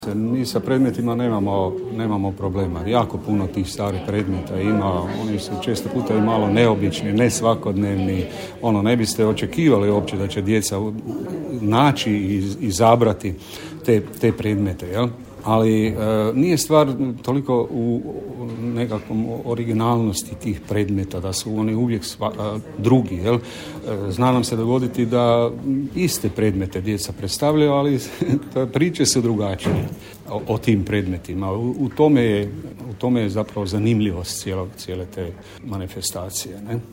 Mali školski kustosi 2022, Muzej Međimurje, 18.5, Čakovec